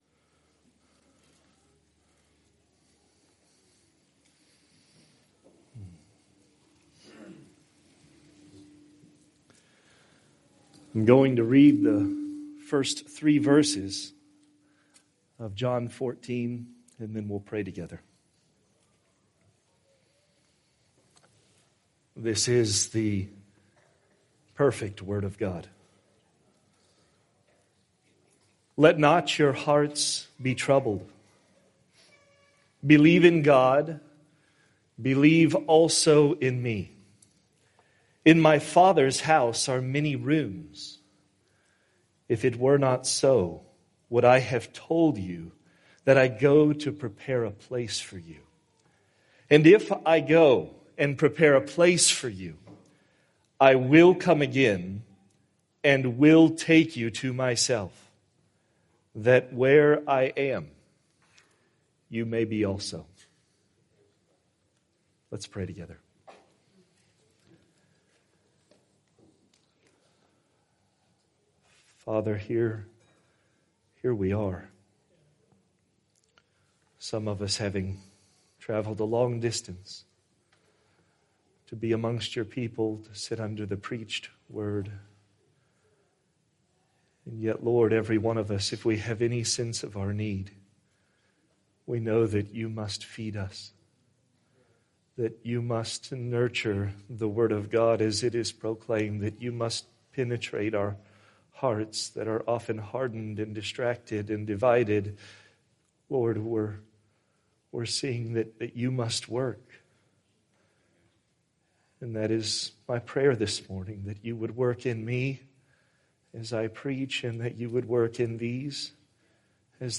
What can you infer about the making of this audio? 2021 Fellowship Conference | Session #2 | John 14:1-4 | The second coming of Jesus Christ shouldn't just be considered as an academic...